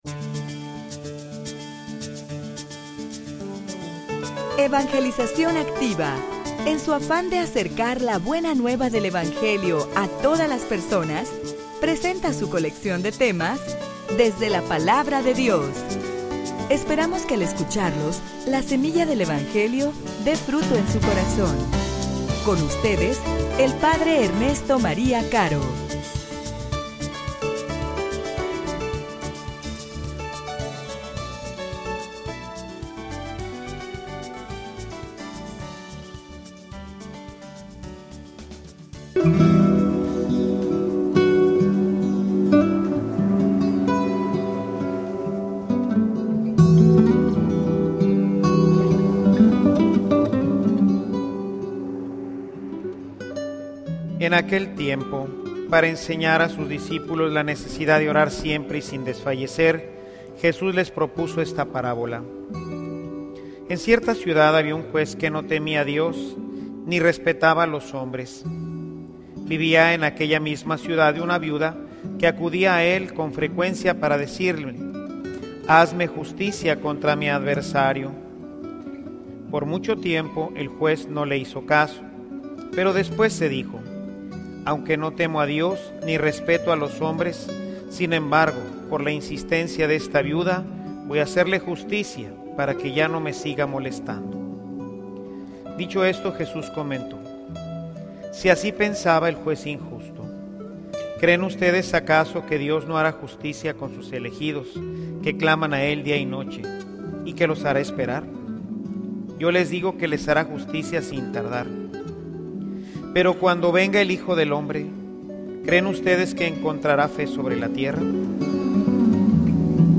homilia_Persevera_y_alcanzaras_la_victoria.mp3